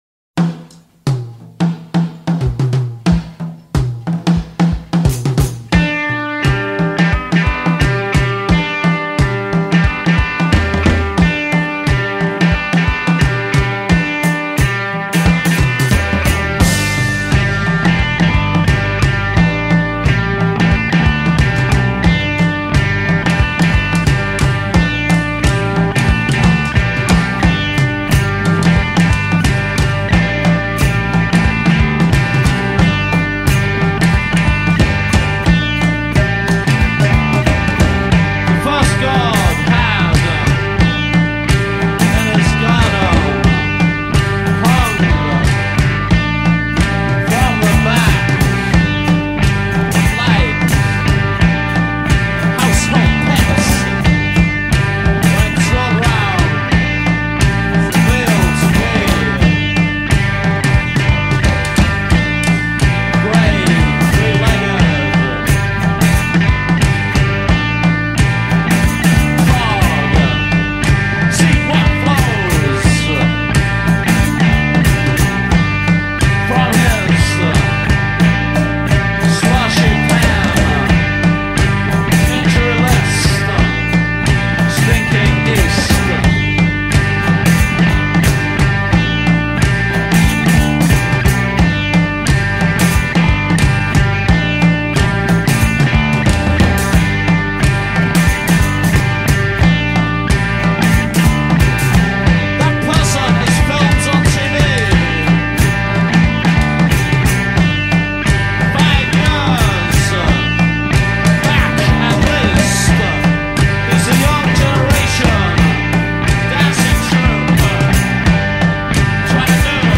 پست-پانک از {{model.count}} محصول مورد نظر موجود نمی‌باشد.